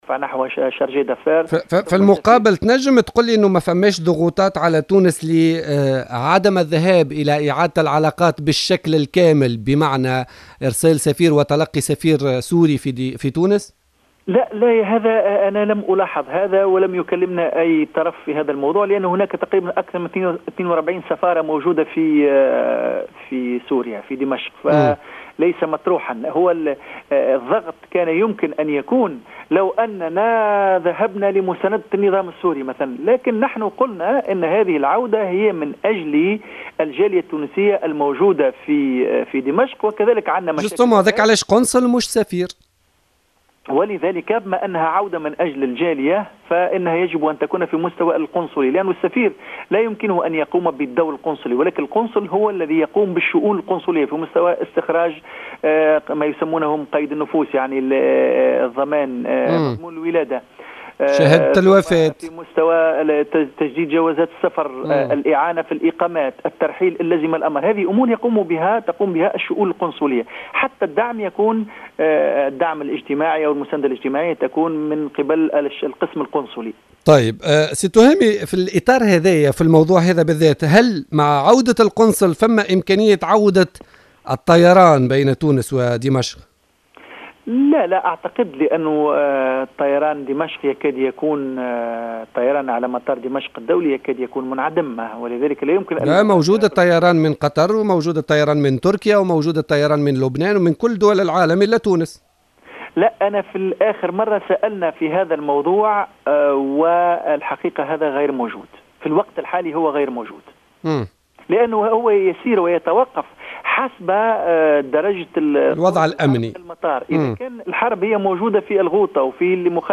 أكد التهامي العبدولي، الوزير المكلف بالشؤون العربية والإفريقية، ضيف بوليتيكا...